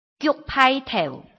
臺灣客語拼音學習網-客語聽讀拼-南四縣腔-入聲韻
拼音查詢：【南四縣腔】giug ~請點選不同聲調拼音聽聽看!(例字漢字部分屬參考性質)